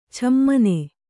♪ chammane